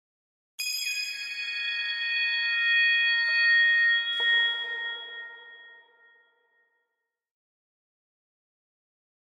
High Strings
Harmonic Plucks Harmonic Pad - Short Very High Pad With Some Pizzicatos Version 2